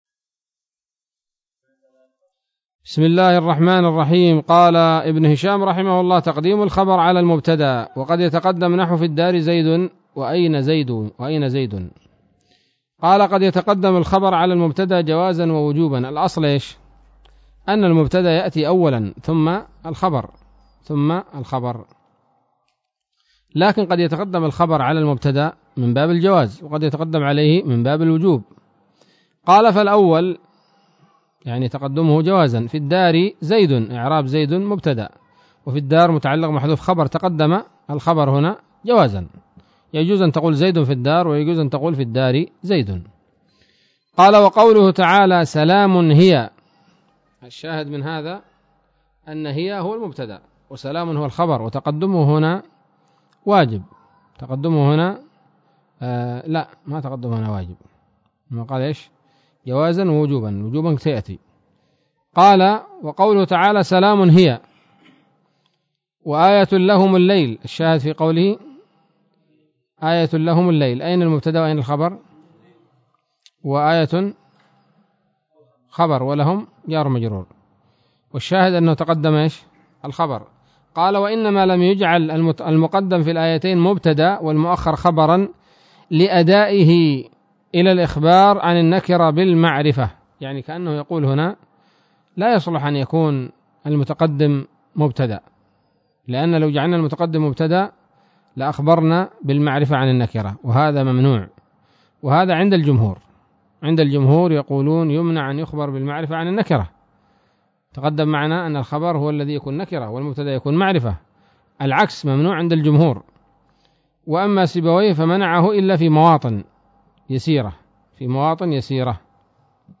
الدرس الرابع والخمسون من شرح قطر الندى وبل الصدى